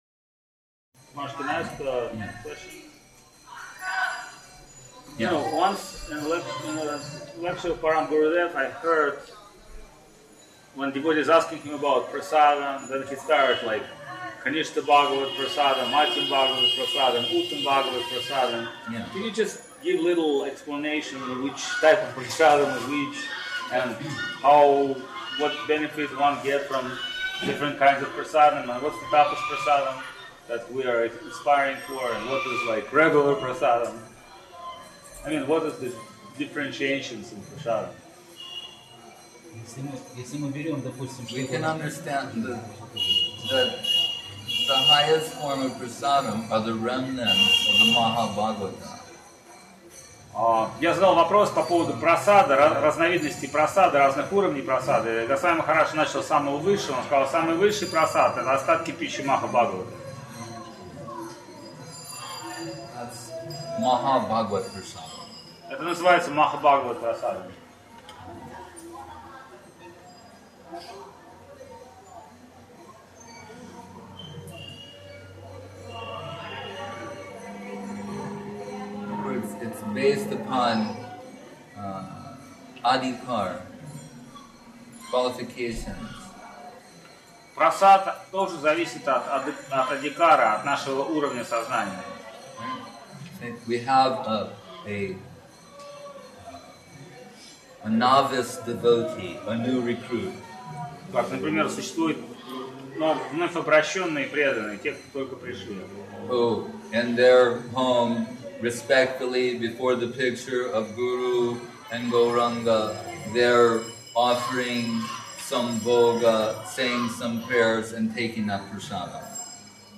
Place: SCSMath Nabadwip